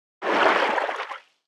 Sfx_creature_seamonkeybaby_swim_slow_05.ogg